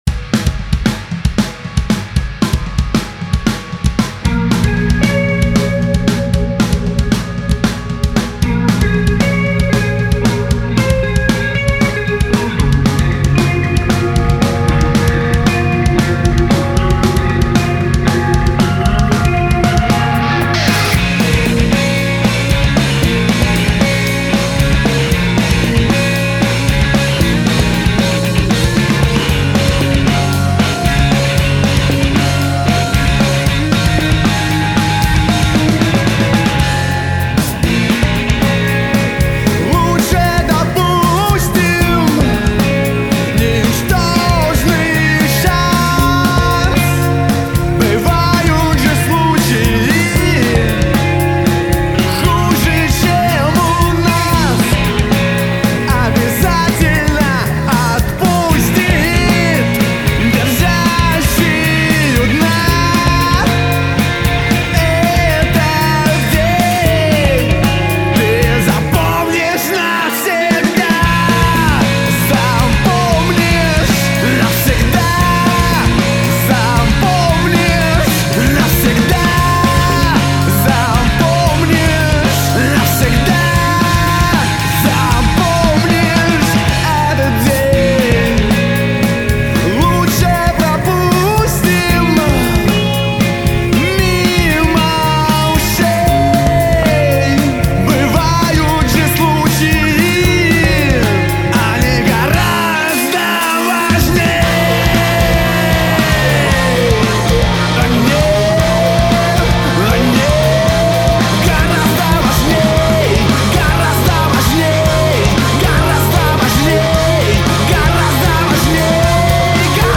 Рок.
Немного не хватает громкости. но дальнейшее компрессия уже не помогает толком, что не верно сделано?